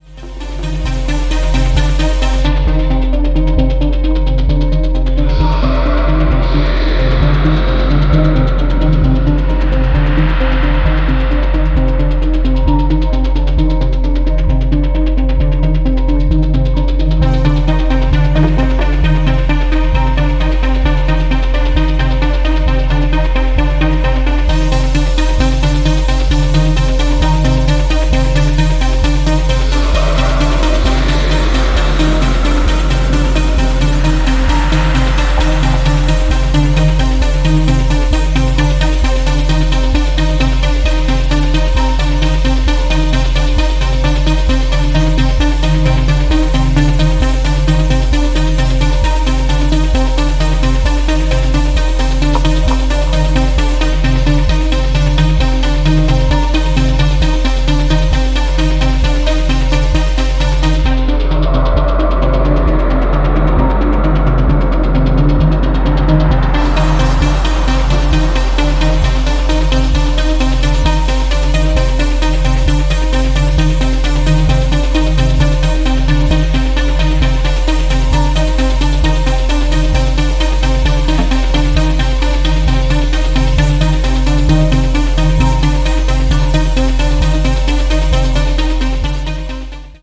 cutting-edge electronic music